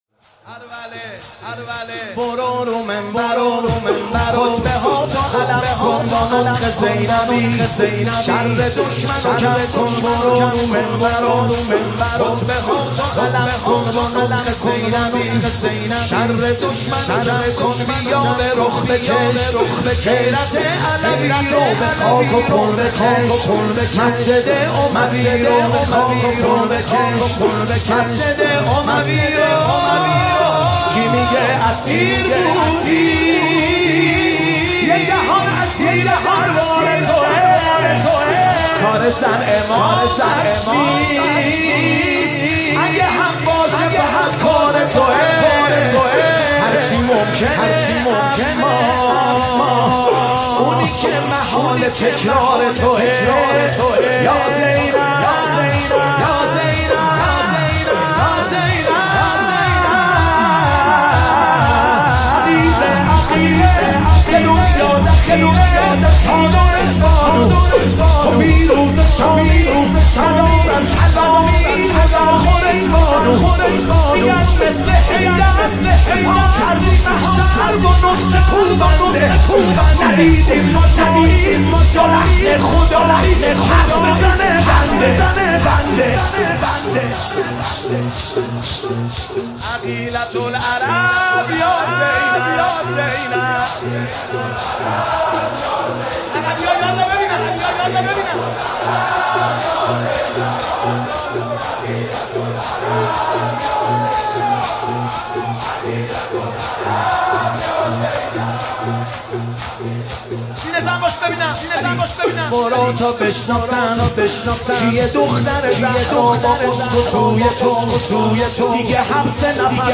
شهادت حضرت زینب ۱۴۰۳